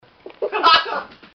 Laugh 33